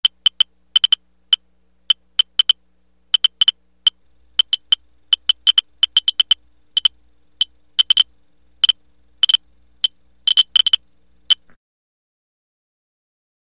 Звуки аномалий
Аппарат издает писк